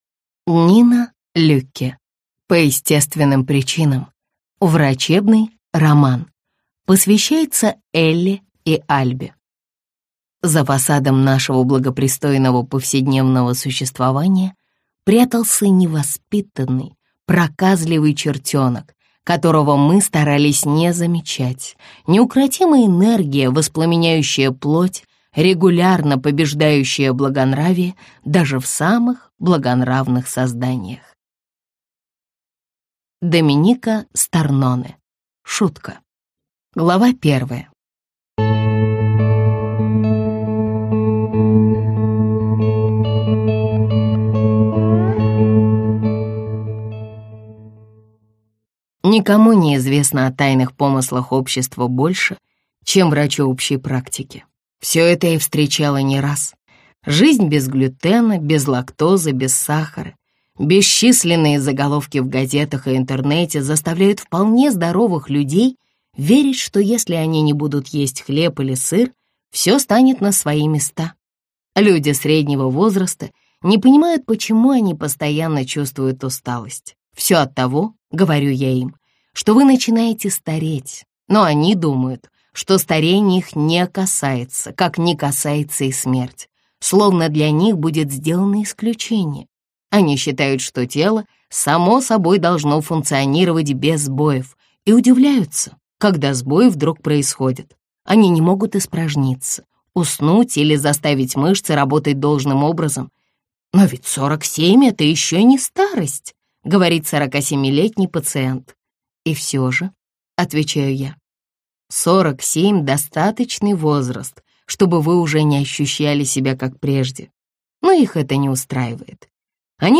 Аудиокнига По естественным причинам. Врачебный роман | Библиотека аудиокниг